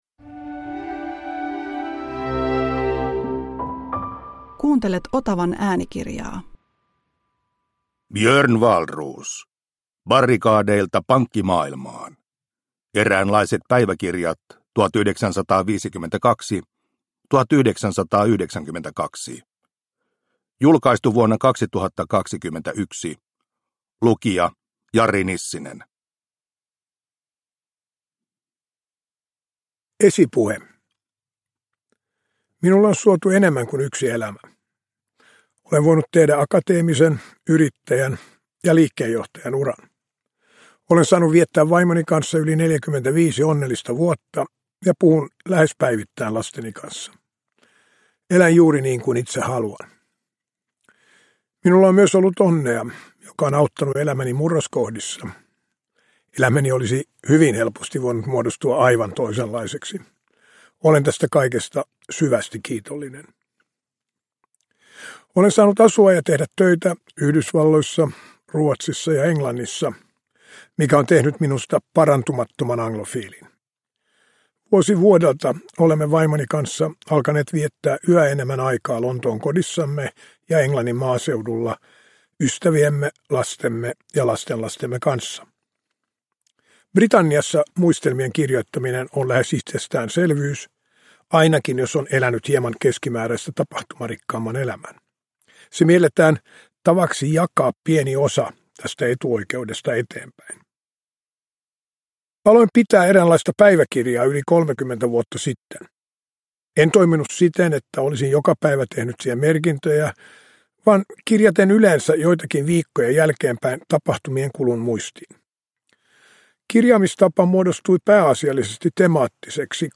Barrikadeilta pankkimaailmaan – Ljudbok – Laddas ner